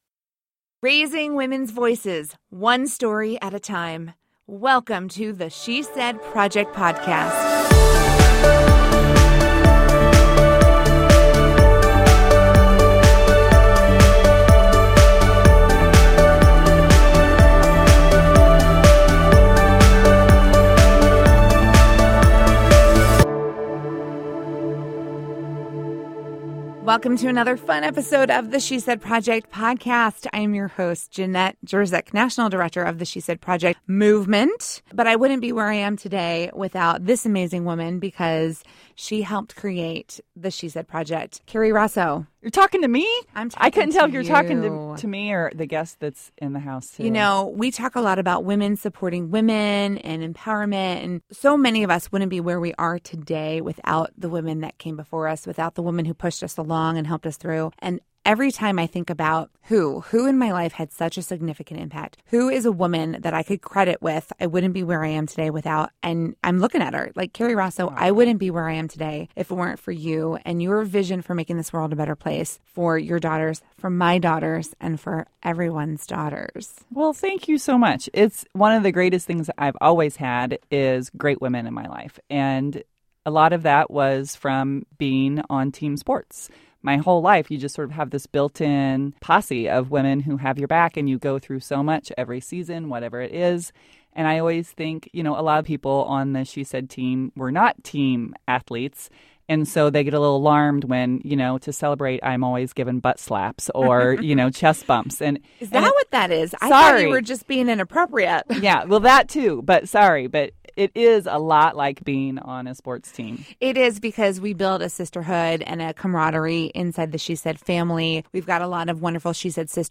In episode 10 of the She Said Project Podcast, she recounts the story from the stage, play by play, as they worked together to overcome each unexpected obstacle they met along the way.